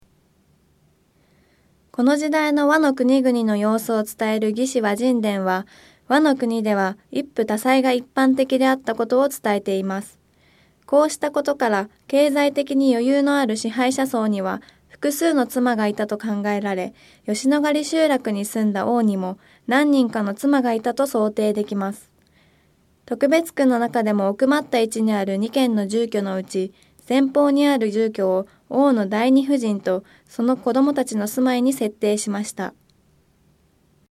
特別区のなかでも奥まった位置にある2軒の住居のうち、前方にあるこの住居を「王」の第二夫人とその子供達の住まいに設定しました。 音声ガイド 前のページ 次のページ ケータイガイドトップへ (C)YOSHINOGARI HISTORICAL PARK